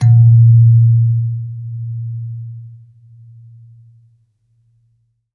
kalimba_bass-A#1-mf.wav